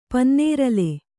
♪ pannērale